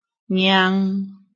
臺灣客語拼音學習網-客語聽讀拼-海陸腔-鼻尾韻
拼音查詢：【海陸腔】ngiang ~請點選不同聲調拼音聽聽看!(例字漢字部分屬參考性質)